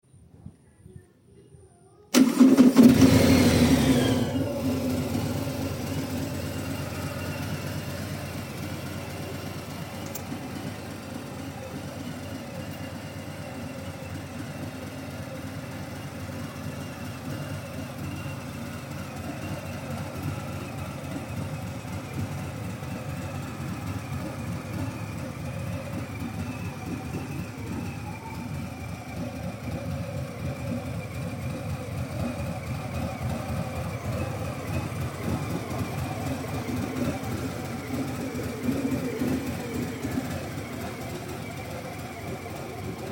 for sale waaa harley davidson sound effects free download